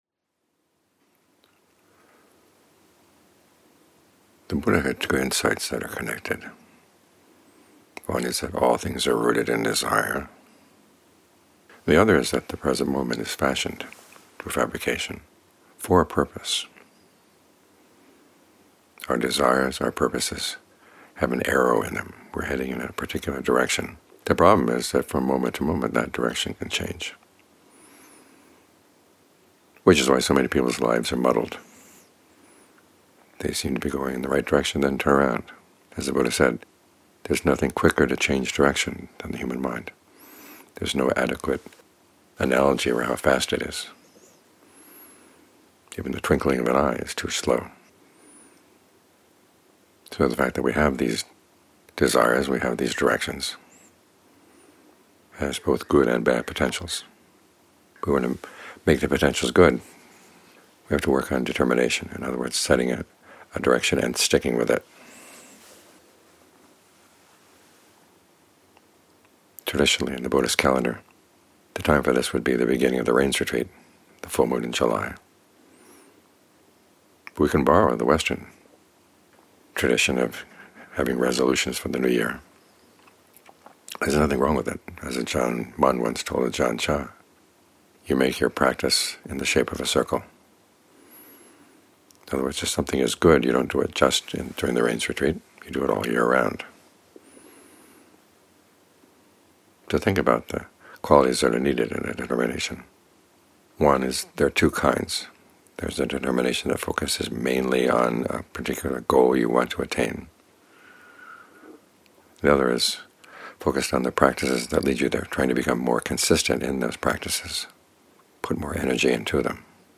A talk by Thanissaro Bhikkhu entitled "Guarding the Truth"